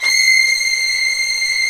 Index of /90_sSampleCDs/Roland LCDP13 String Sections/STR_Violins V/STR_Vls8 Agitato